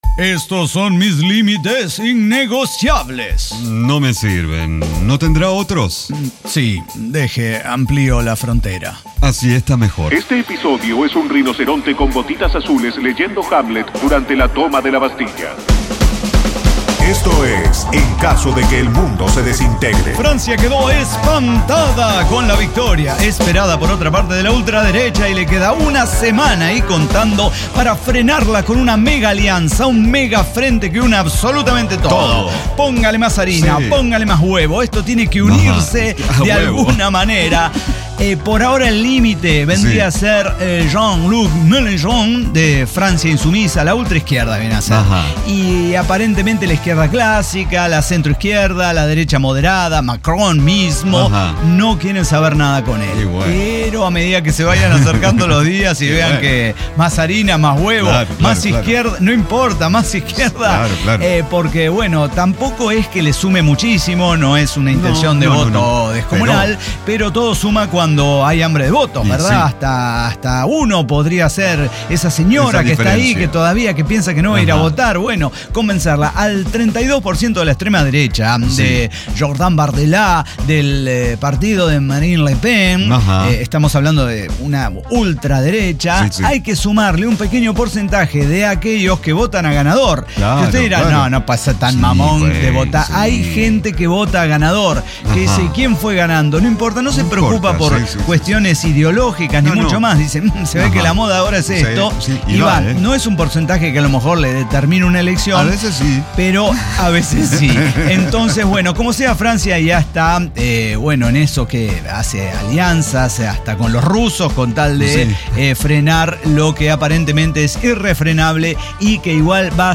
ECDQEMSD podcast El Cyber Talk Show
Diseño, guionado, música, edición y voces son de nuestra completa intervención humana.